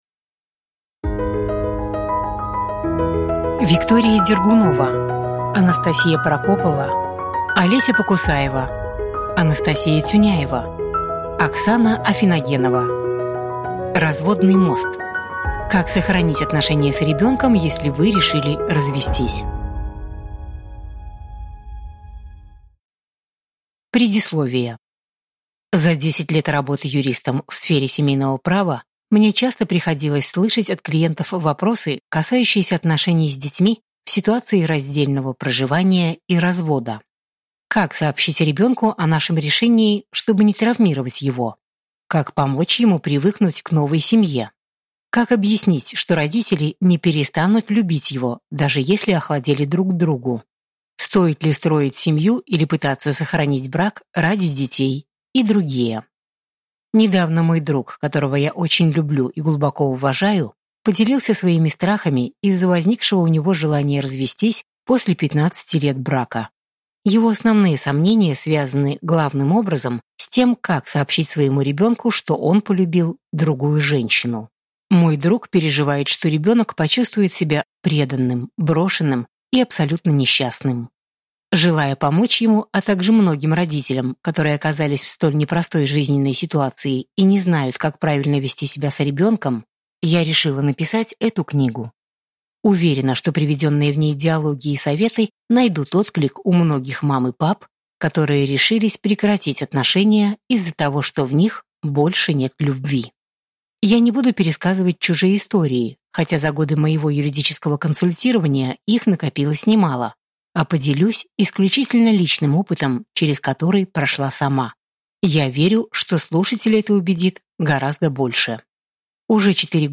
Аудиокнига Разводный мост. Как сохранить отношения с ребенком, если вы решили развестись | Библиотека аудиокниг